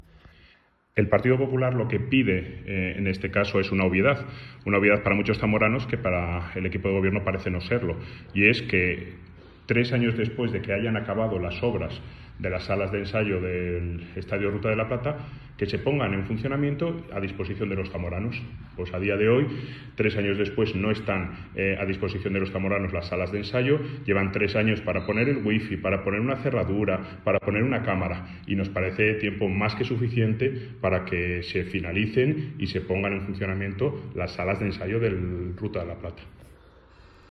Declaraciones del Portavoz del Grupo Popular, Jesús María Prada